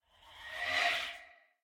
snapshot / assets / minecraft / sounds / mob / vex / idle1.ogg